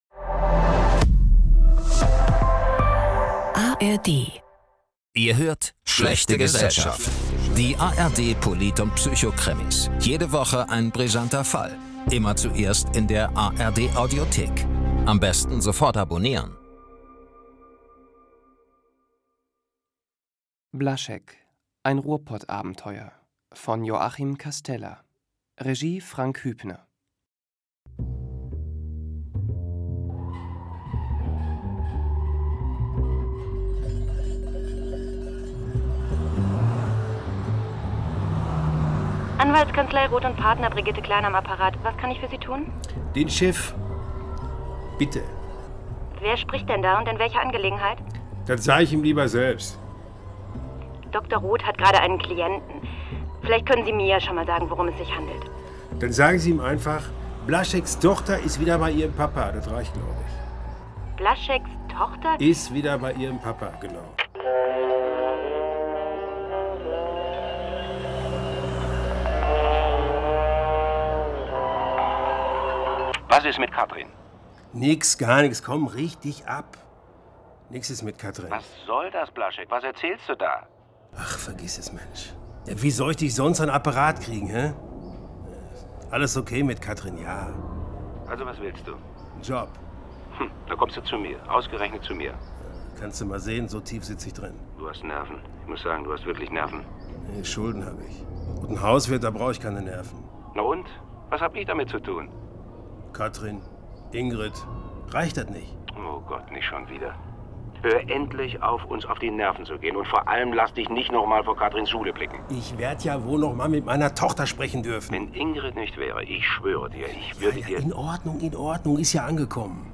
Blaschek – Ruhrpottkrimi ~ Schlechte Gesellschaft - ARD Polit- und Psychokrimis Podcast